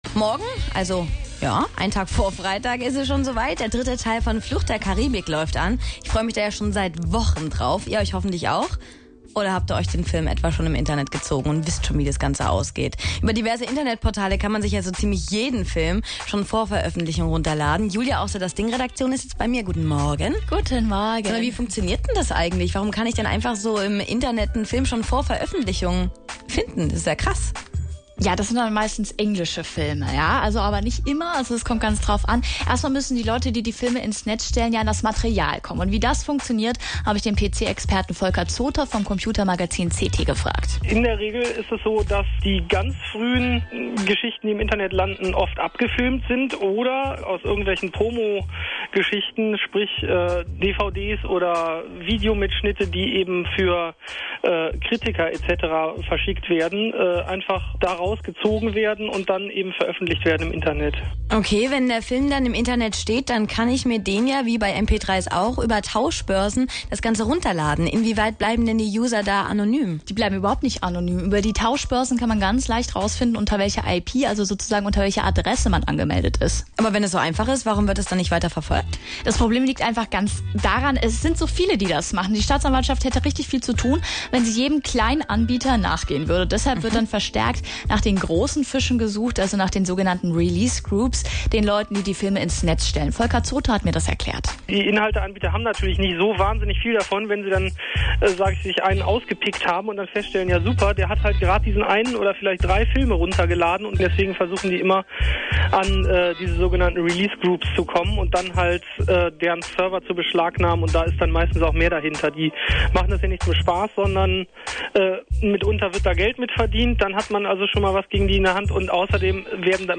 „Weil das Thema […] kein Spaß ist, haben wir uns auch mit einem Anwalt über die rechtliche Seite unterhalten – für Filmpiraterie können einem bis zu fünf Jahre Haft drohen.